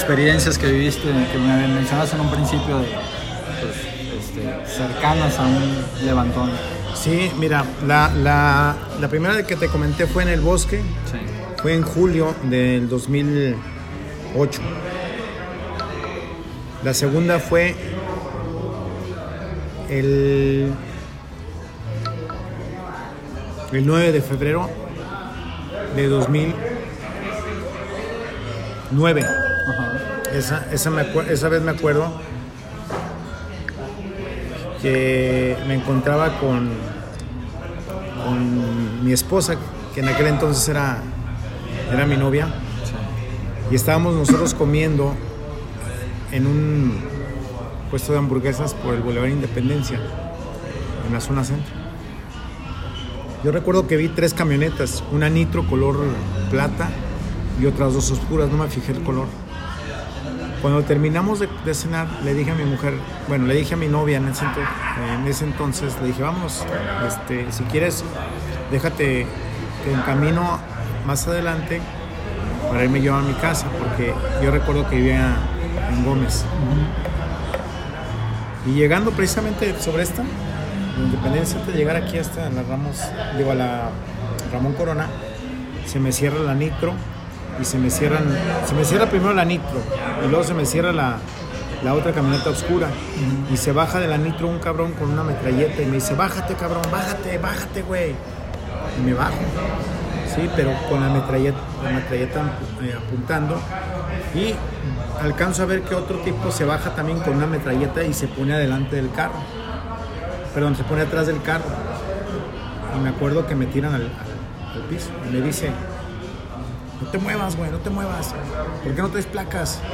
Subserie: Entrevistas